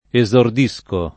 esordire
esordisco [ e @ ord &S ko ], ‑sci